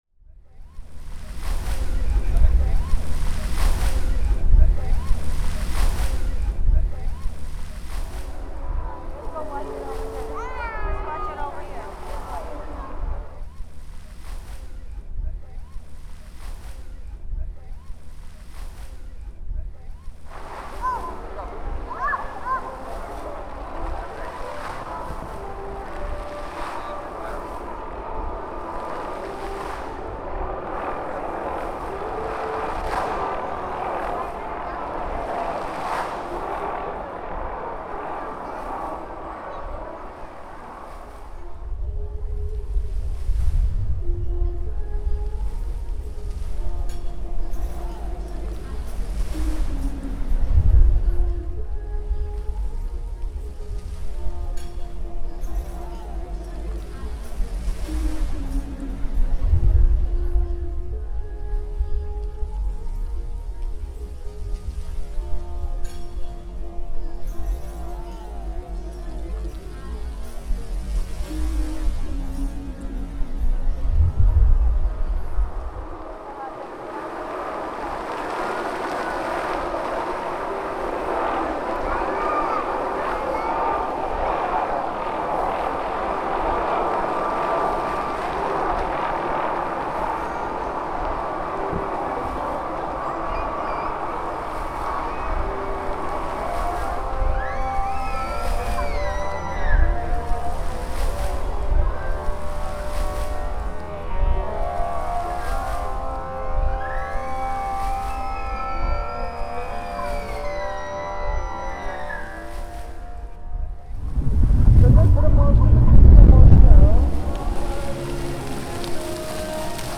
Participants went on sound walks, produced ‘scores’ depicting soundscapes near their homes, collected field recordings of those soundscapes, and learned to edit these recordings in Reaper.
The soundscape I encountered here was surprisingly more dynamic than one might expect; one can easily distinguish between the rhythmic pulses of the dancing fountain from the variety of voices, sounds, and dialects of the passersby. The textures you hear in this composition have been extracted, engineered, and blended to create a mood that is mellow yet alive and constrained, like our society in today’s quarantine era.
The narrative of this work is less melodically structured than it is acoustically programmed; if you listen carefully, you can piece together a story of casual curiosity, agitation, and charm.”